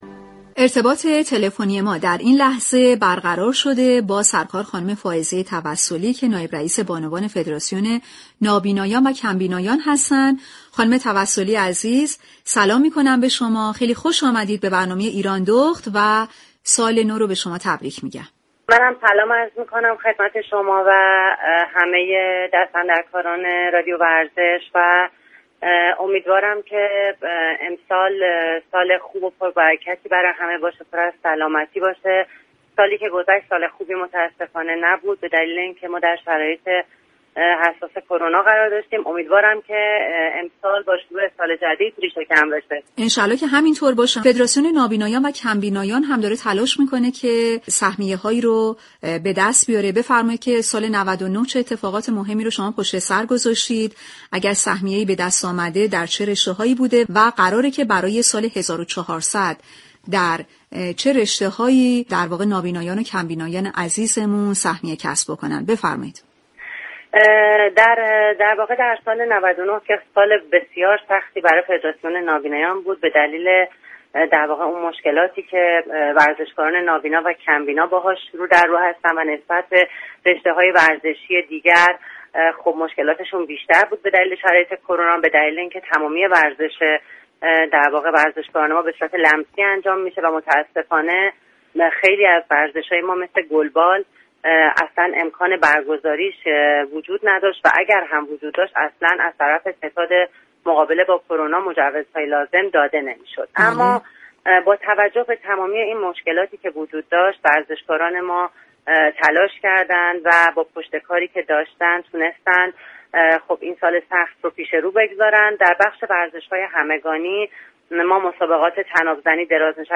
شما می توانید از طریق فایل صوتی پیوست شنونده این گفتگو باشید. ویژه برنامه نوروزی«ایرانداخت» به مصاحبه با بانوان افتخارآفرین ورزش ایران می پردازد.